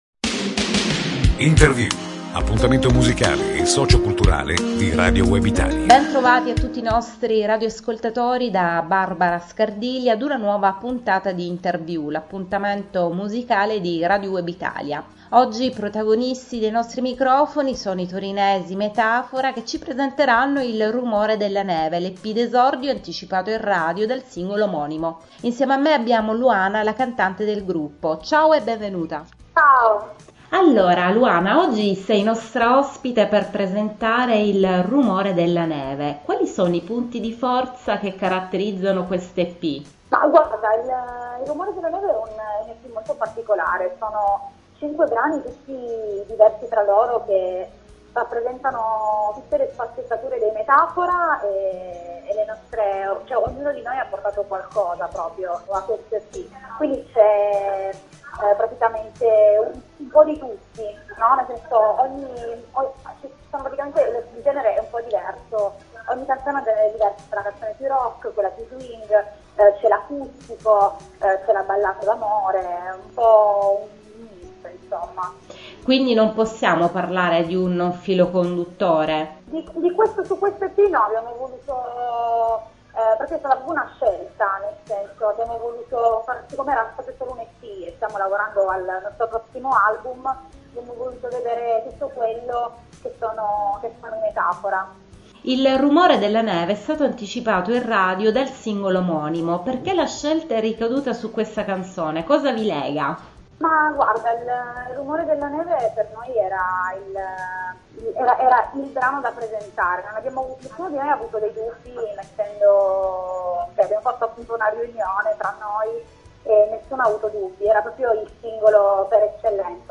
Intervista alla band torinese dei Metaphora